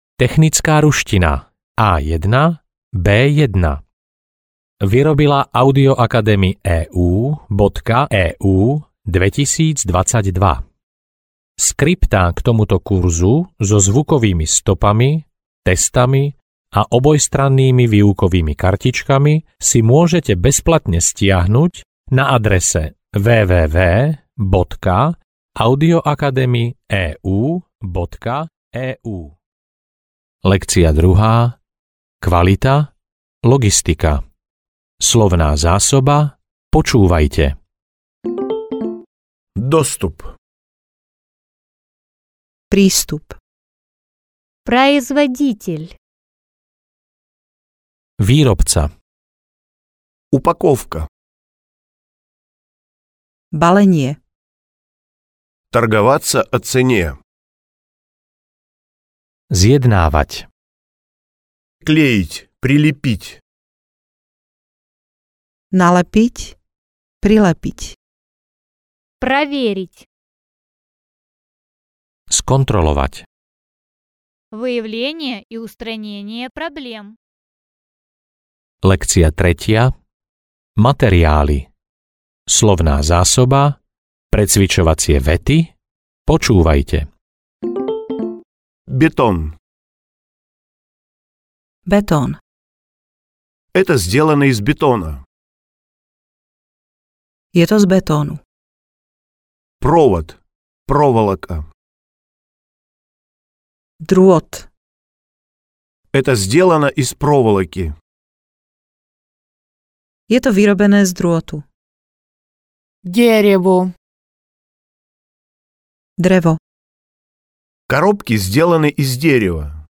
Technická ruština A1-A2 audiokniha
Ukázka z knihy